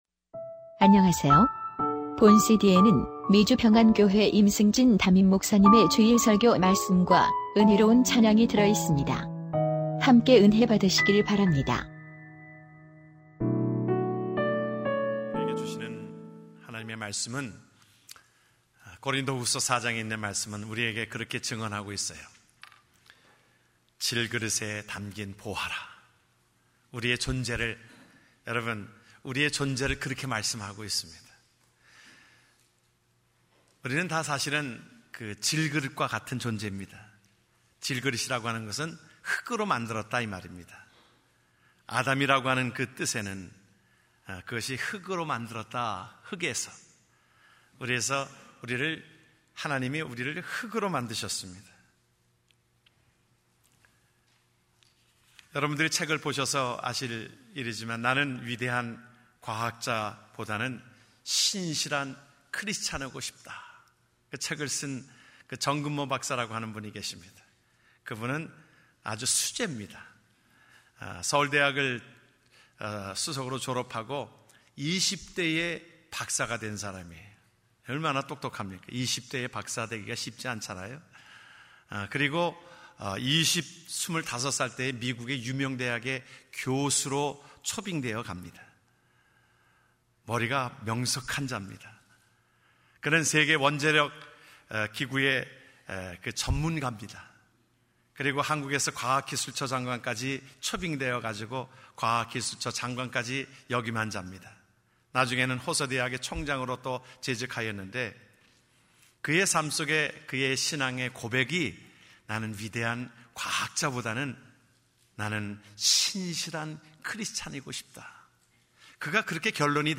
주일설교말씀